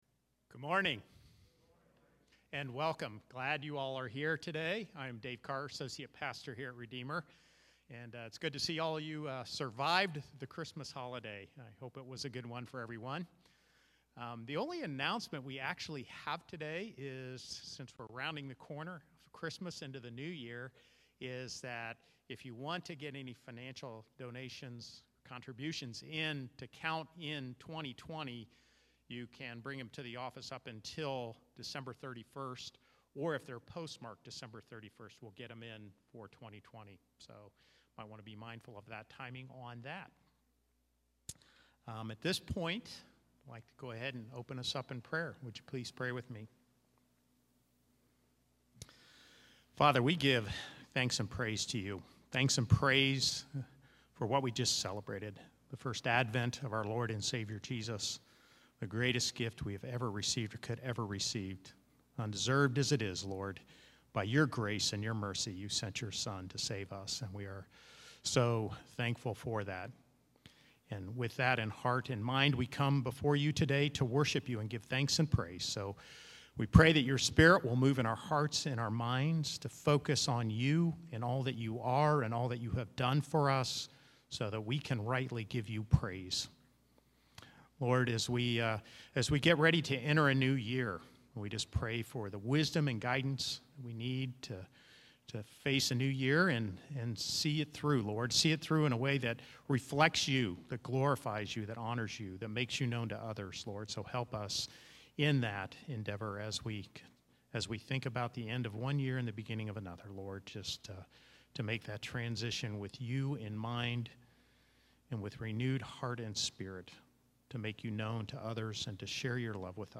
December 27, 2020 Worship Service
Service Type: Live Service